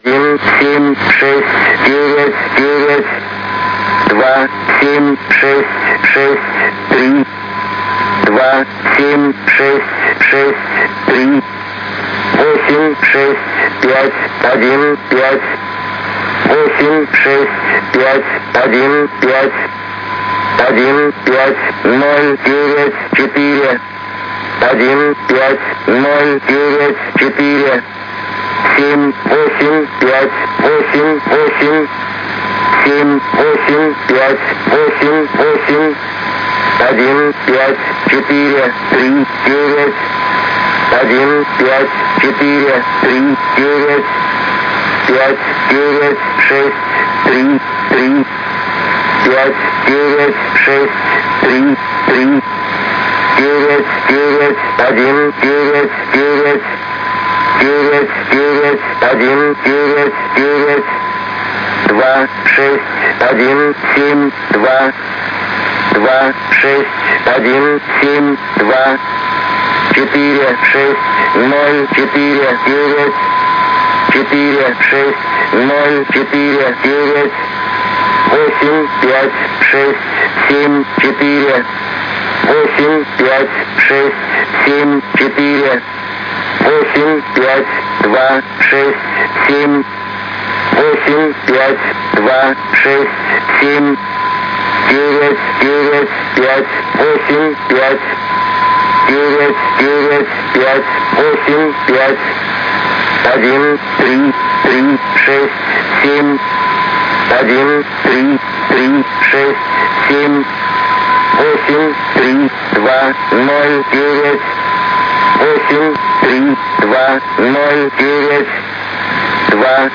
15721 кГц, номерная станция (предположительно), записано 31.05.2011г. в 04:10 UTC
Фрагмент передачи.
Видимо, номерная станция.
на частоте 15721 кГц.